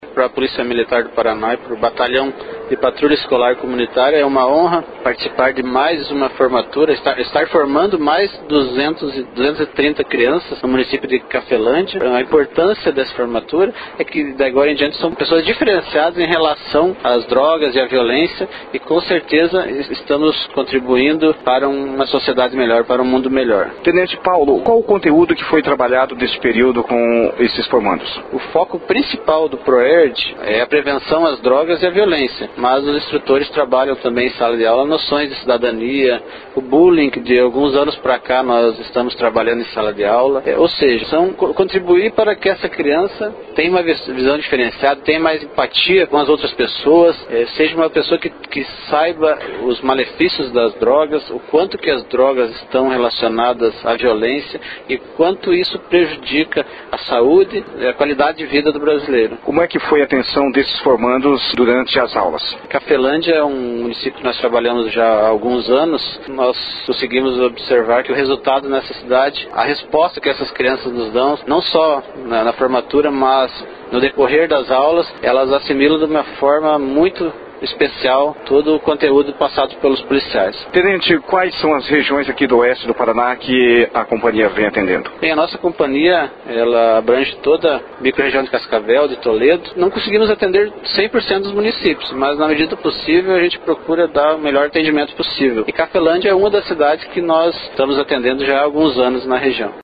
Secretária de Educação Viviane Vissovati